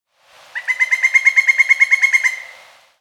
Pileated Woodpecker: Juvenile Female, 1 call
This bird was in the back of my yard, first feeding on a rotten red maple trunk on the ground and then up in an ash.
I made the sound recording as a video with my Canon R5, the footage out of focus just so I could capture the sound. The young bird yelled two or three times, but this is the only time I managed to record it.
Pileated_Woodpecker__Juvenile_Female_1_call.mp3